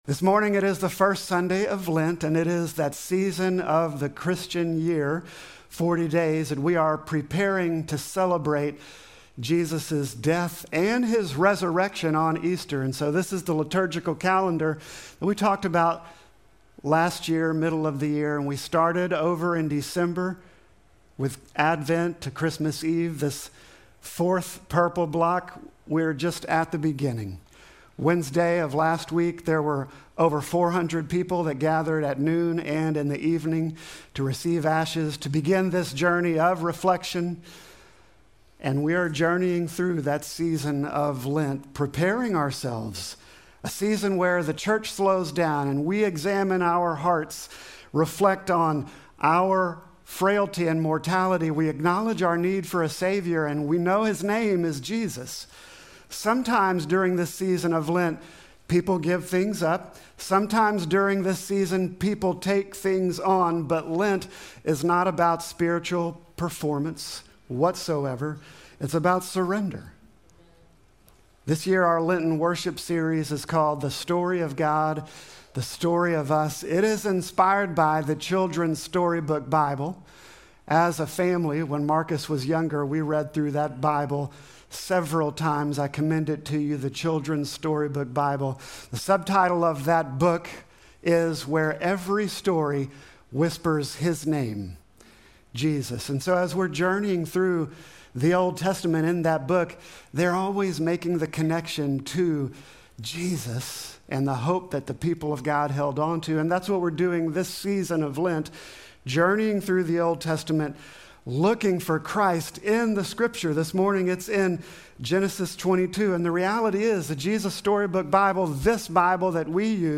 Sermon text: Genesis 22:1-14